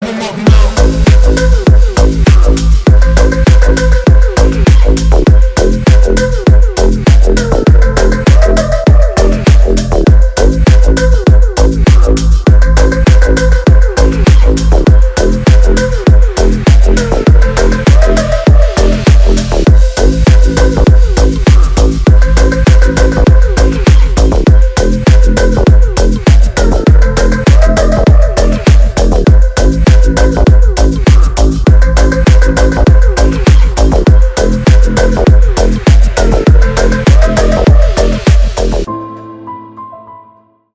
Громкие рингтоны